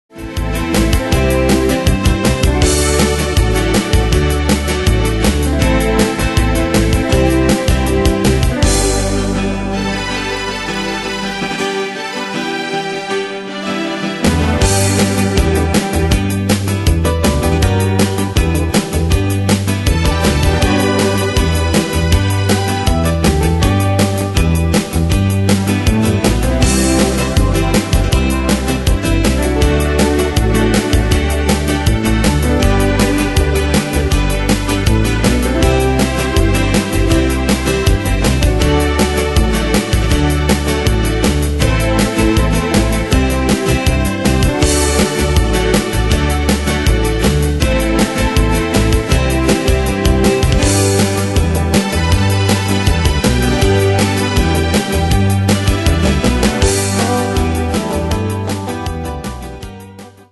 Style: PopAnglo Année/Year: 1975 Tempo: 160 Durée/Time: 3.27
Danse/Dance: Rock Cat Id.
Pro Backing Tracks